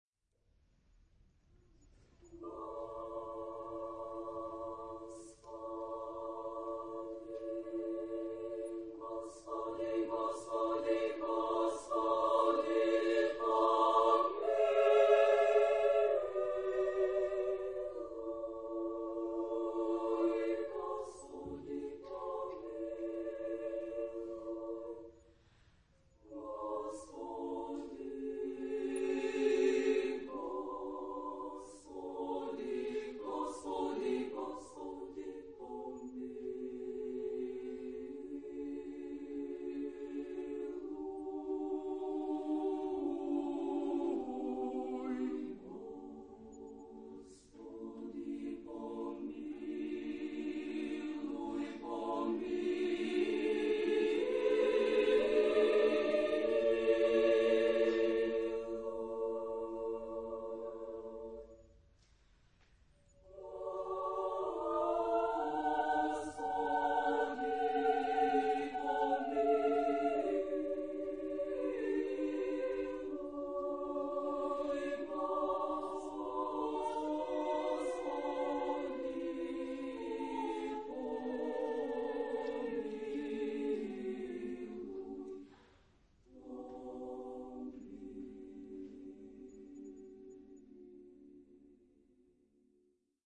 Genre-Style-Forme : Prière
Caractère de la pièce : pieux
Type de choeur : SSA  (3 voix égales de femmes )